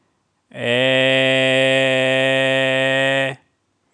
Vocale în format .wav - Vorbitorul #19